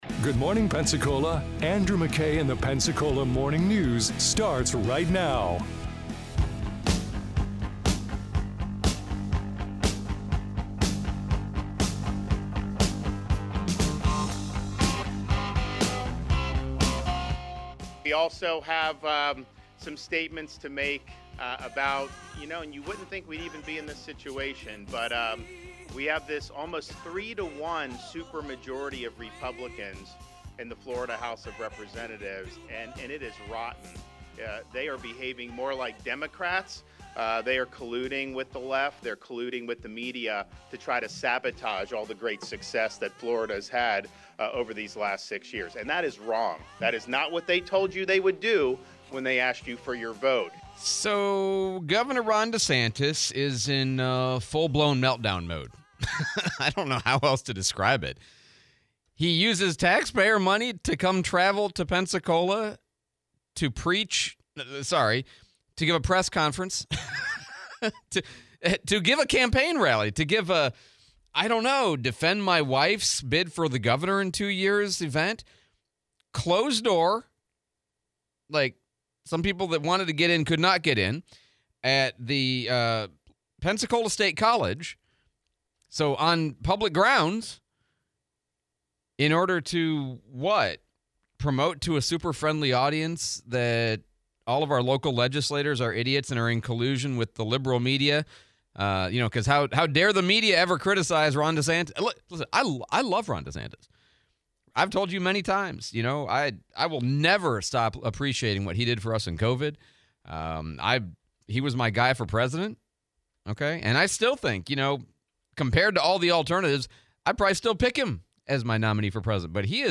Gov. Desantiss press conference in Pensacola, Replay of Sheriff Chip Simmons